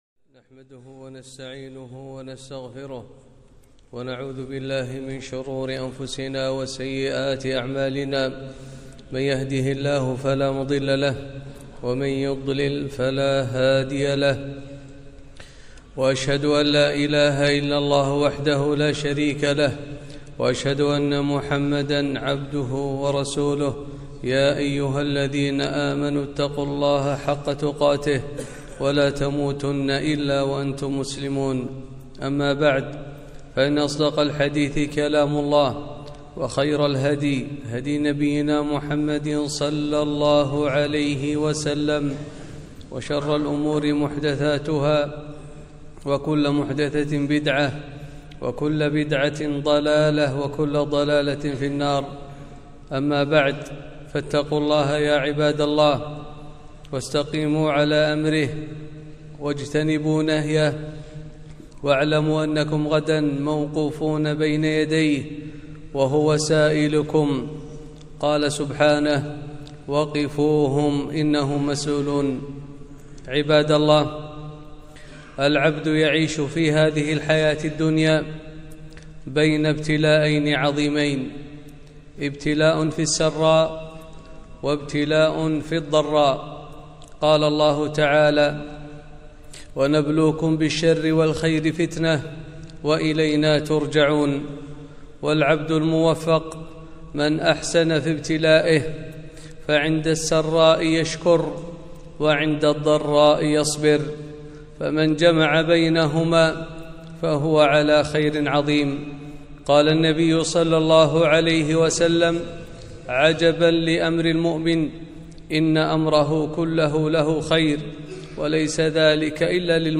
خطبة - الصبر فضله ومنزلته